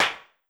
GAR Clap.wav